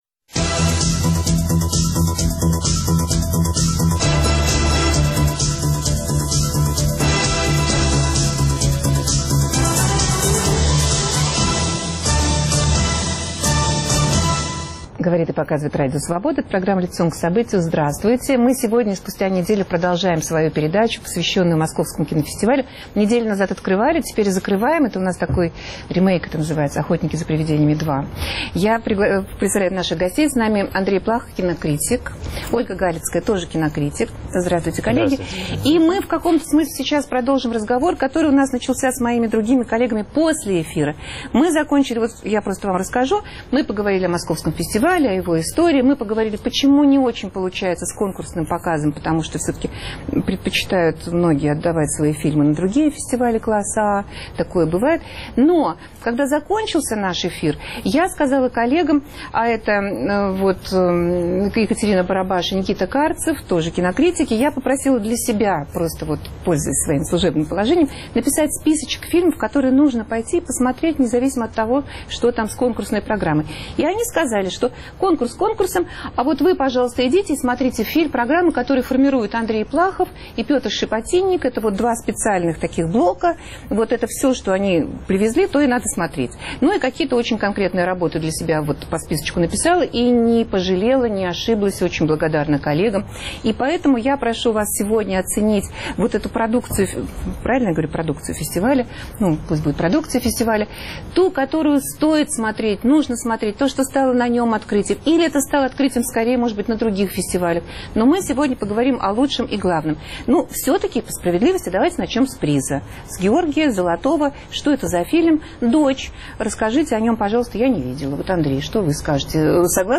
За какие фильмы стоит сказать "спасибо" Московскому кинофестивалю? Размышляют кинокритики